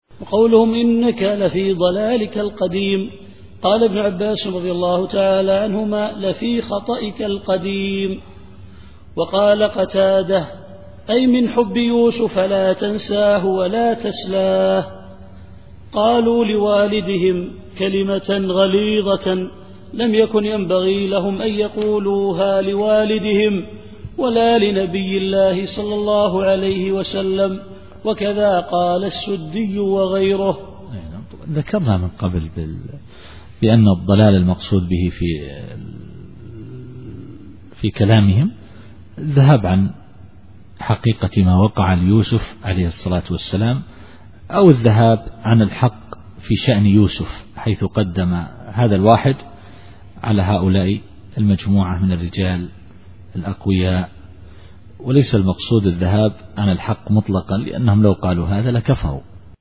التفسير الصوتي [يوسف / 95]